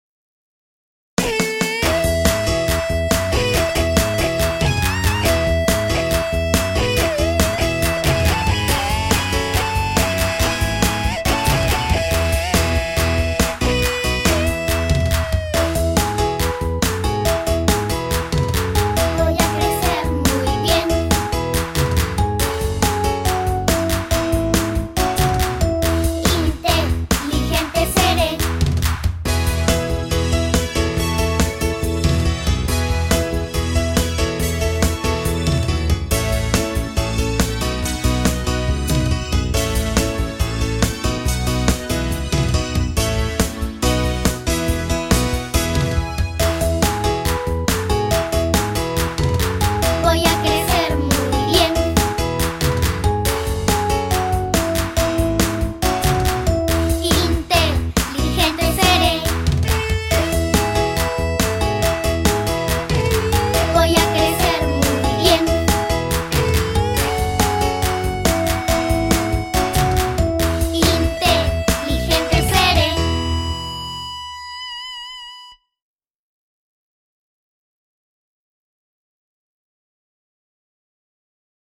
(karaoke)